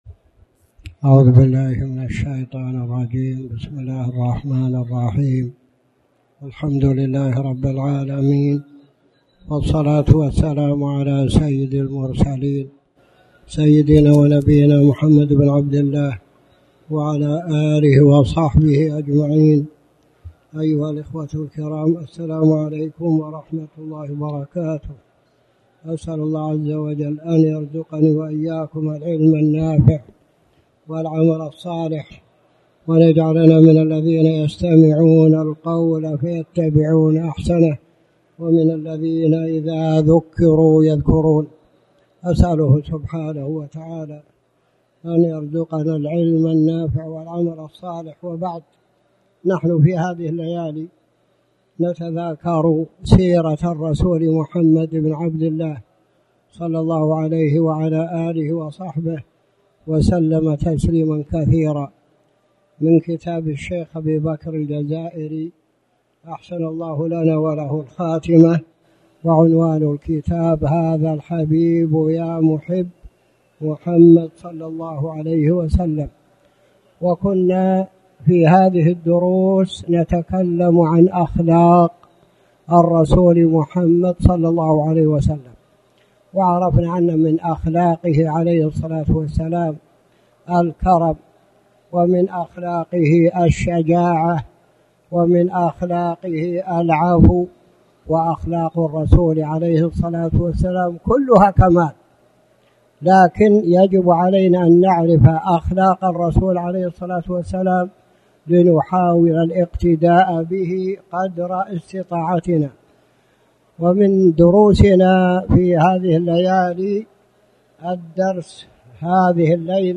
تاريخ النشر ١٢ محرم ١٤٣٩ هـ المكان: المسجد الحرام الشيخ